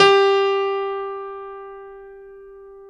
SG1 PNO  G 3.wav